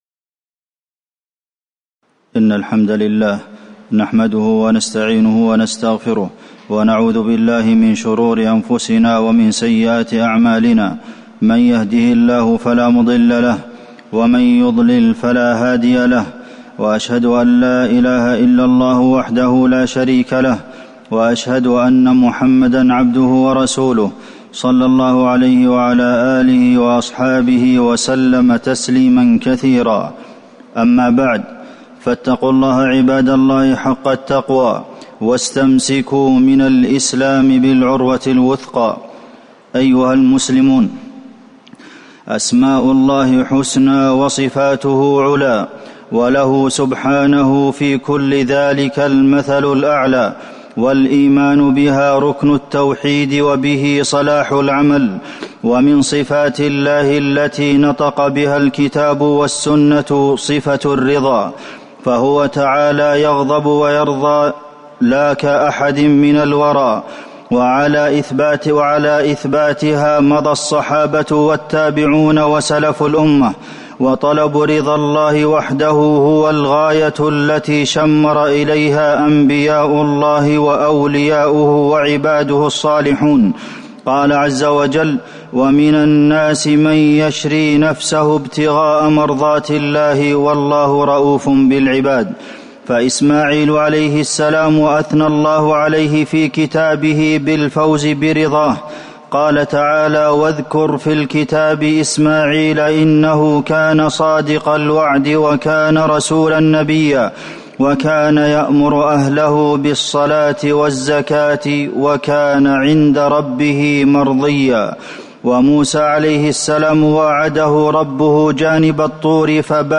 تاريخ النشر ٥ صفر ١٤٤١ هـ المكان: المسجد النبوي الشيخ: فضيلة الشيخ د. عبدالمحسن بن محمد القاسم فضيلة الشيخ د. عبدالمحسن بن محمد القاسم رضا الله